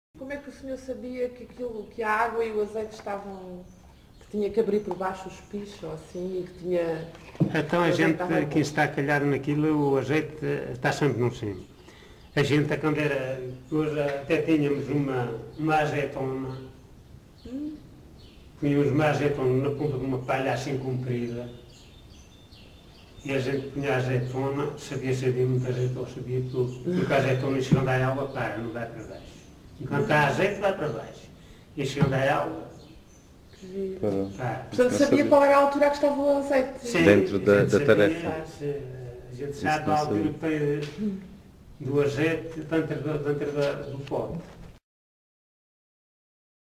LocalidadePorto de Vacas (Pampilhosa da Serra, Coimbra)